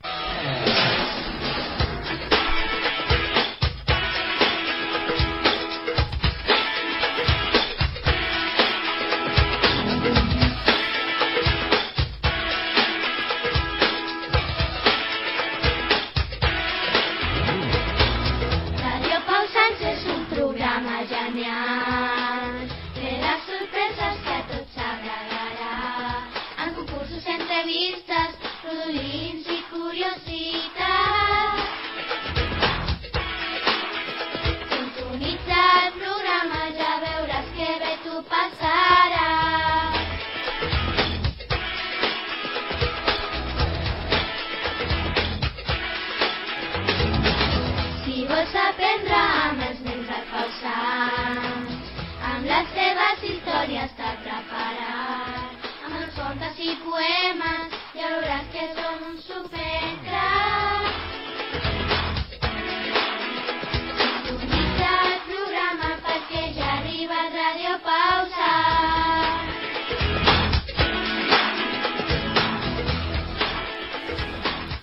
Sintonia cantada de l'emissora